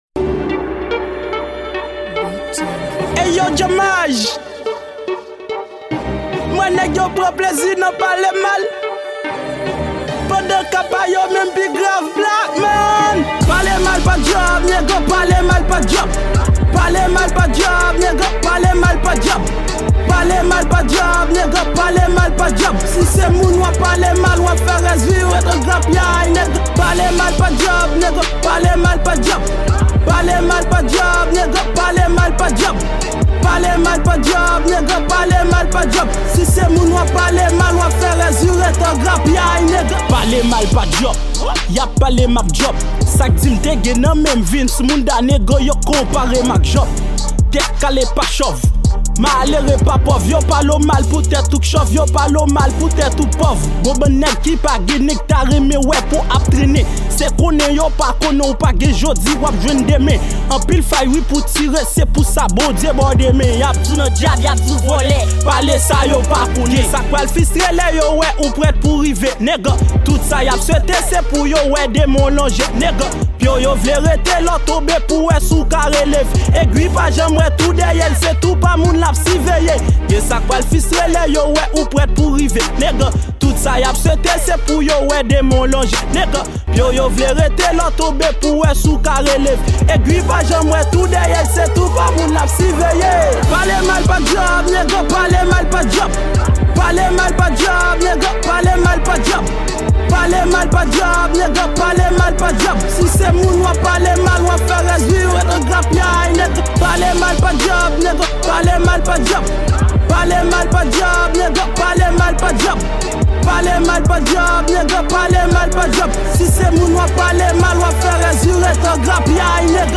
Genre: Trap.